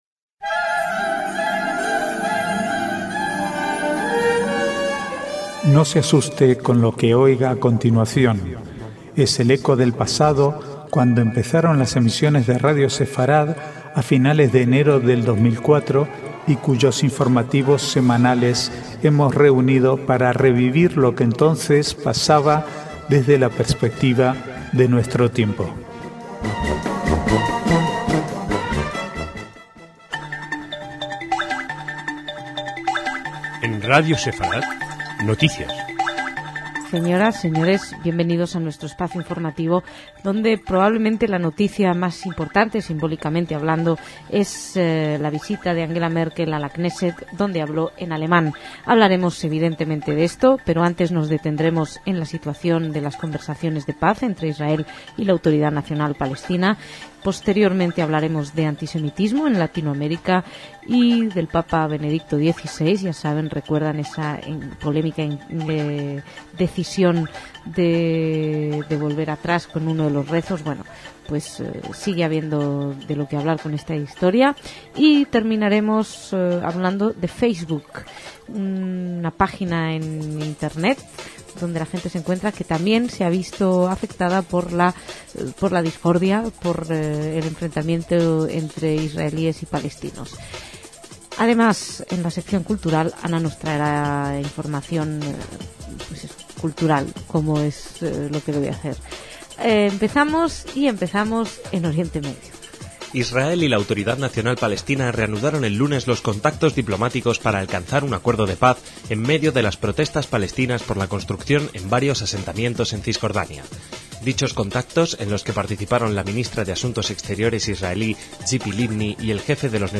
Archivo de noticias del 19 al 21/3/2008